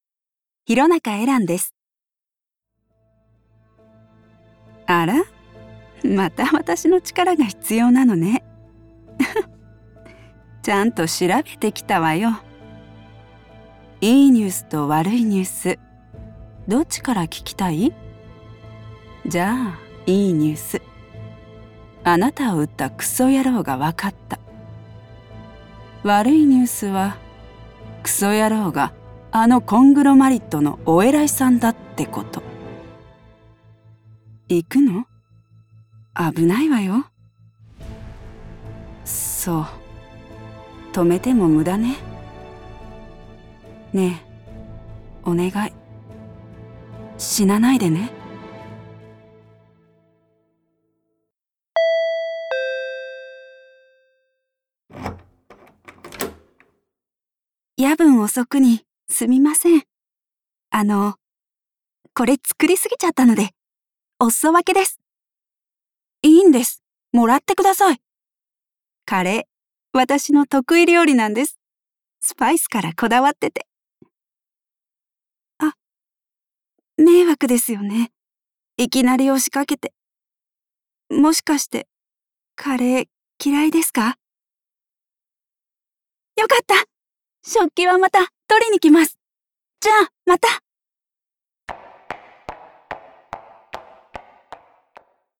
Voice Over